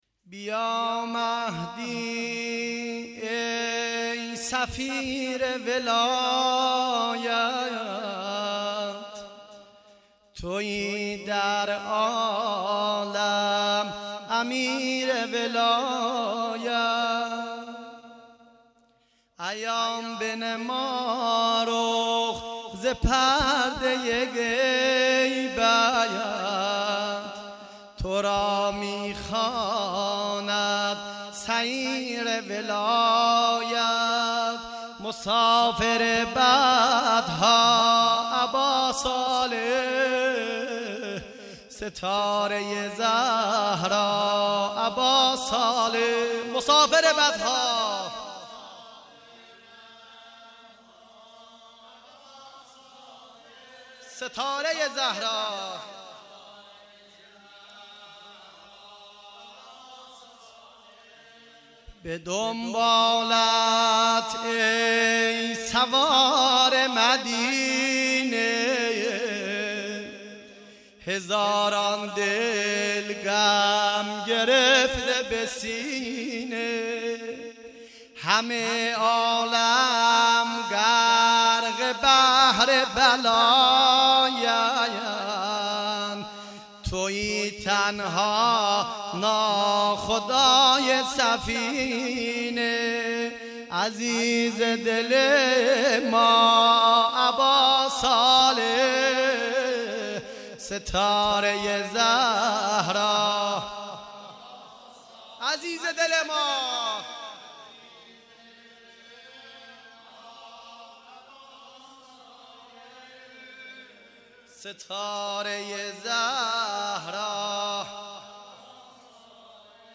جلسه هفتگی
اعیاد شعبانیه میلاد امام زمان(عج)